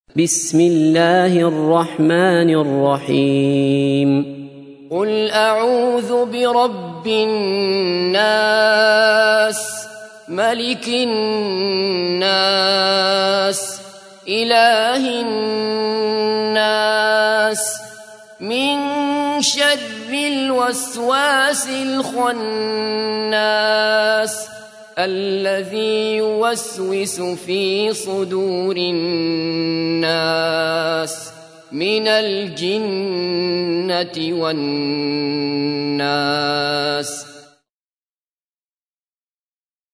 تحميل : 114. سورة الناس / القارئ عبد الله بصفر / القرآن الكريم / موقع يا حسين